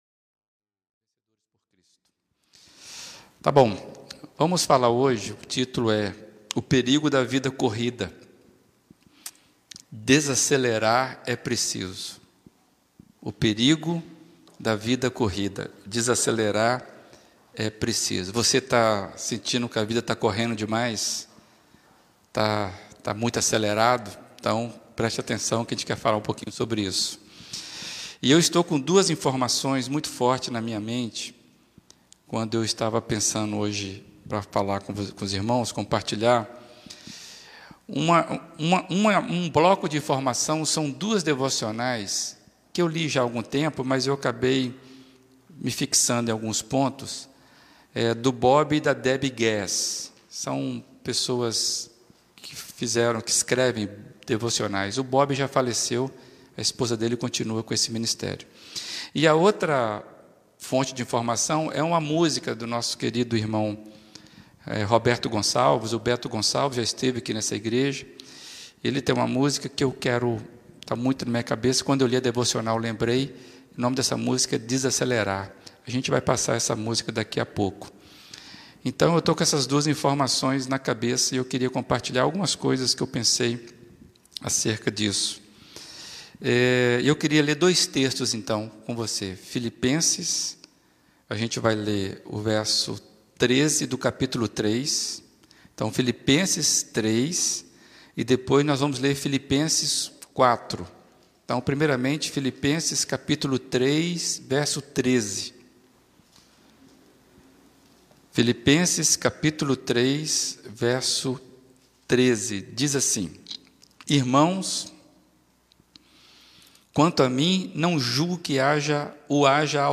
Mensagem
na Primeira Igreja Batista de Brusque